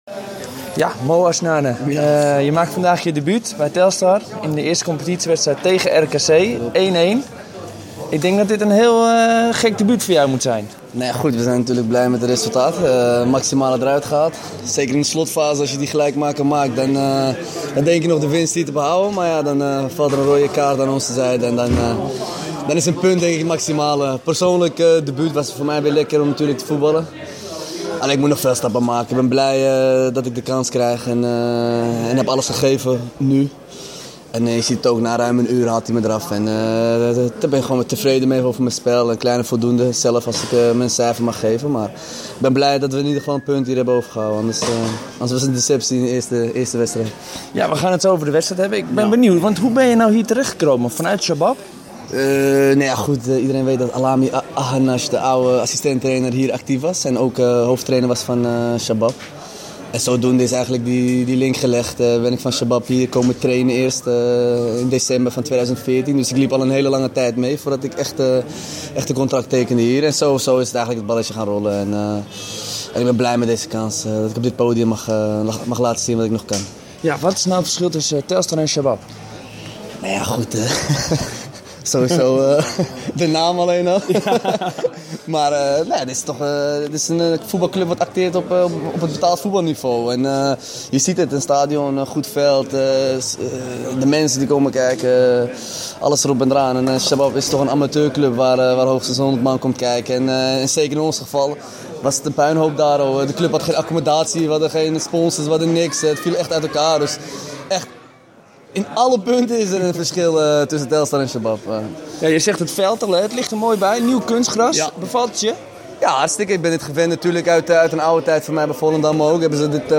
Wedstrijdritme verslaggever